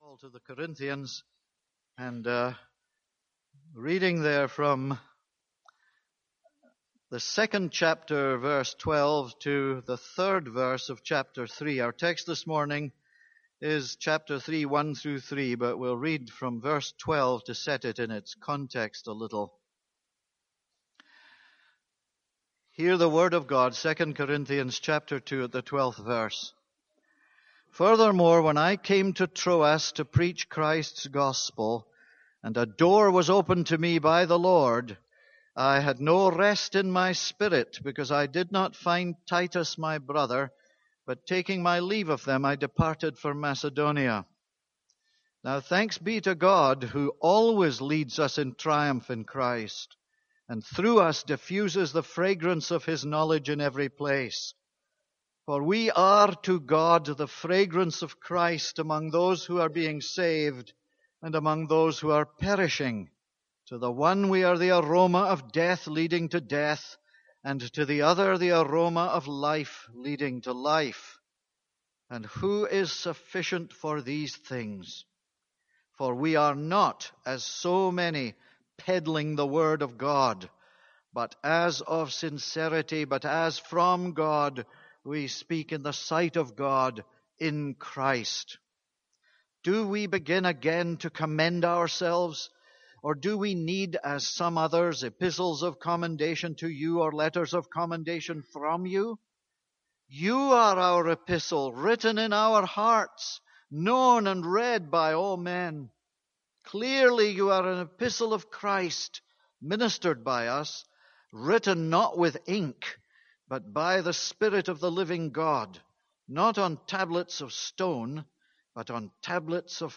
This is a sermon on 2 Corinthians 2:12-3:3.